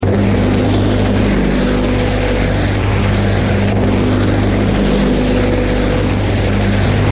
beam.mp3